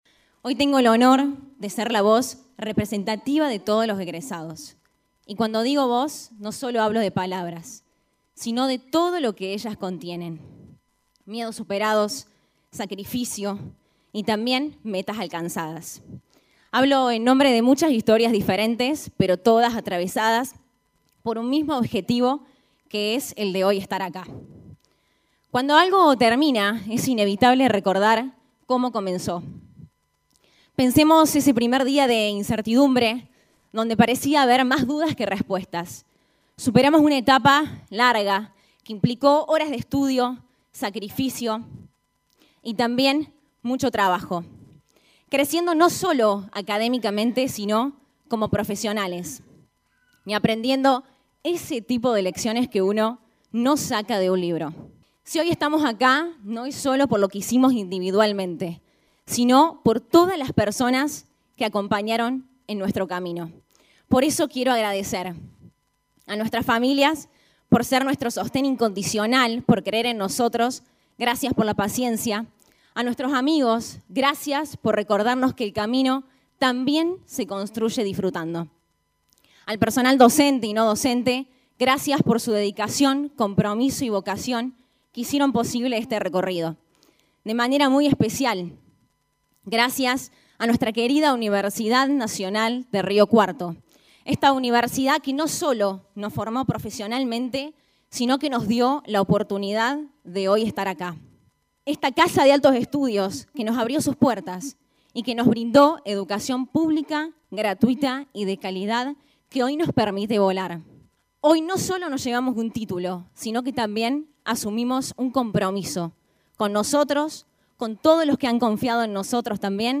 Se realizó una nueva colación en la UNRC
El acto se realizó en dos ceremonias en el aula mayor del campus.